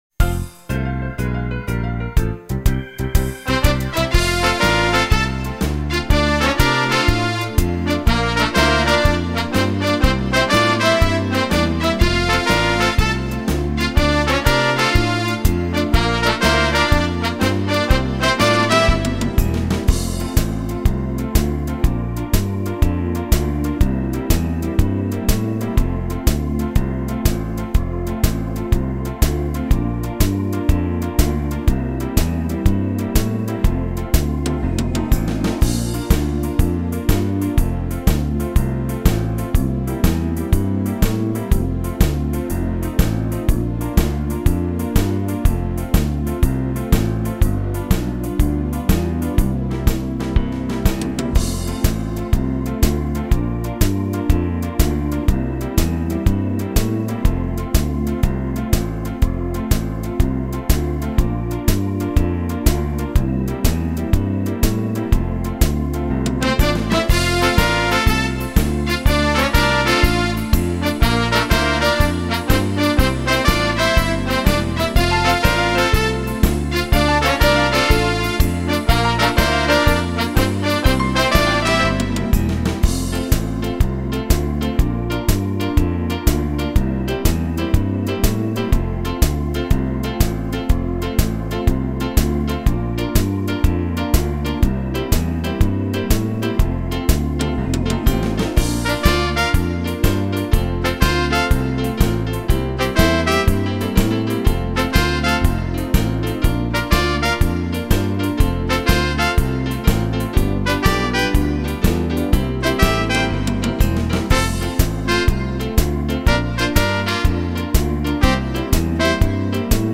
Madison